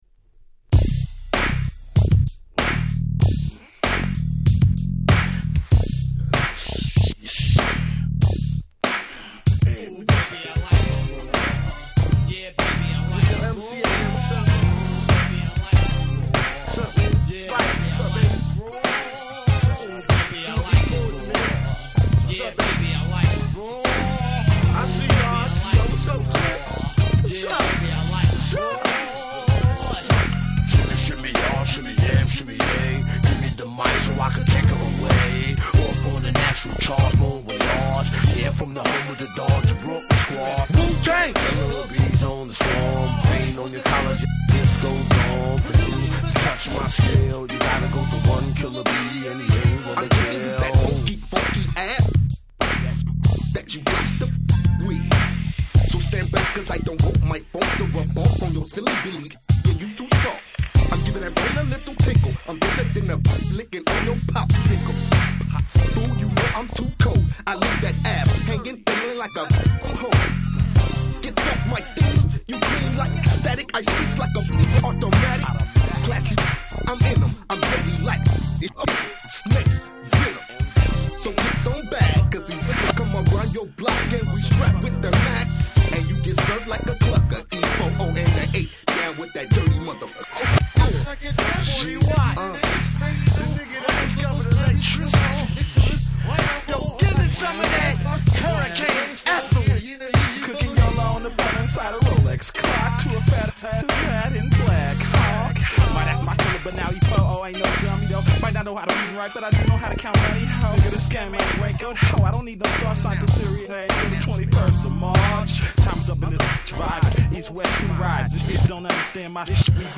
95' Smash Hit Hip Hop !!